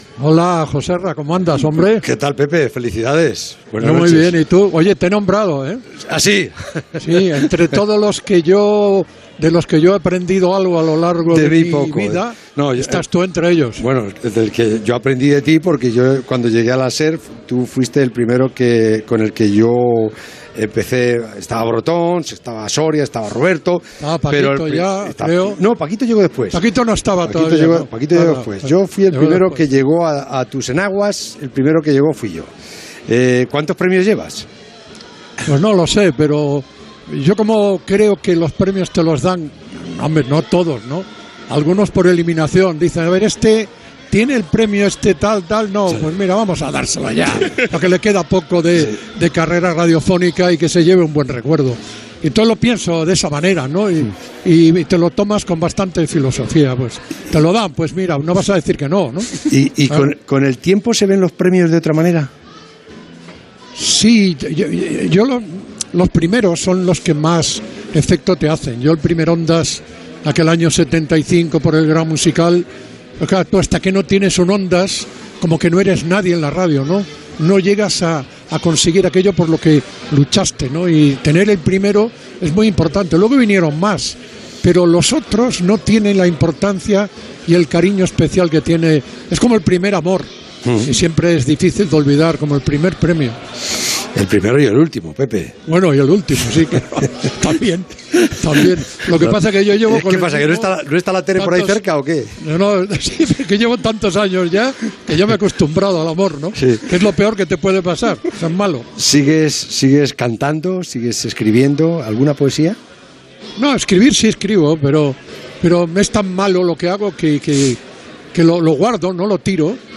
Entrevista a Pepe Domingo Castaño que acabava de rebre un premi de l'Asociación de la Prensa Madrileña
Esportiu